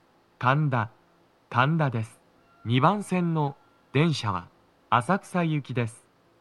スピーカー種類 TOA天井型
🎵到着放送
足元注意喚起放送の付帯は無く、フルは比較的鳴りやすいです。